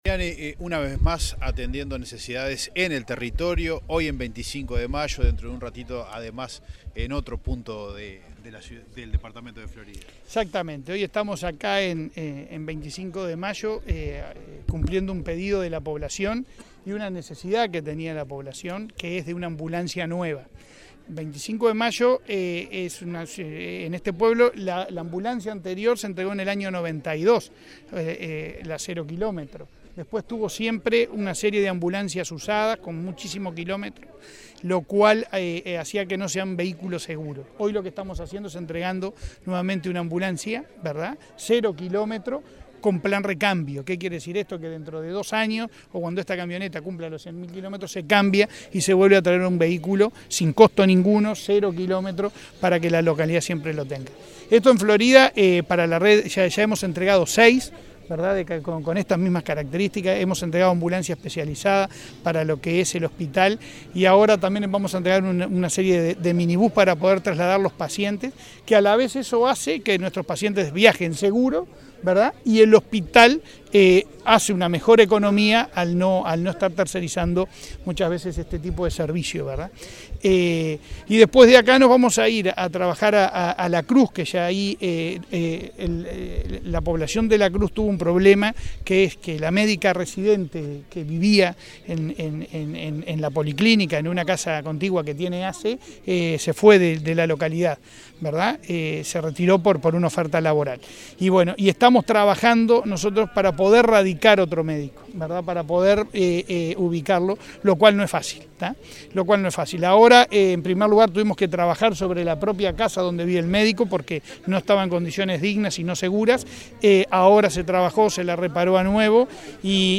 Declaraciones del presidente de ASSE, Leonardo Cipriani, a la prensa
Tras el acto, el presidente de ASSE continuó el recorrido por varias localidades de Florida, y realizó declaraciones a la prensa.